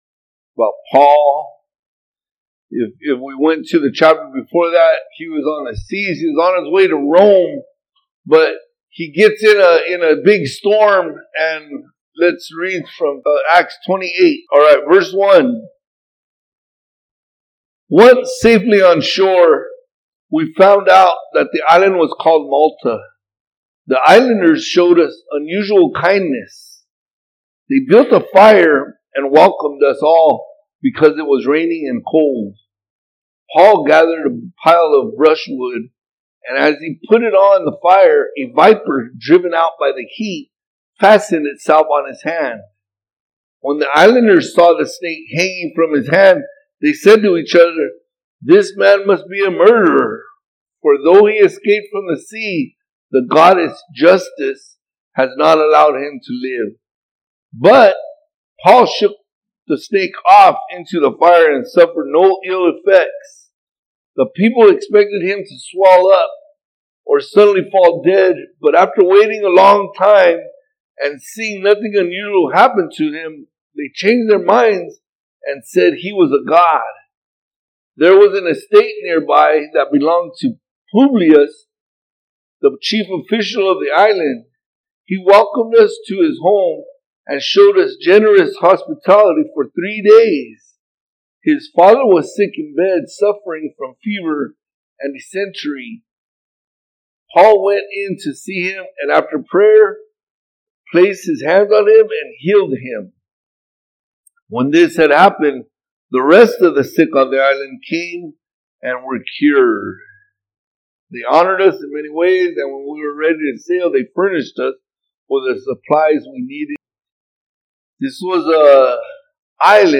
All Sermons Shipwrecked August 16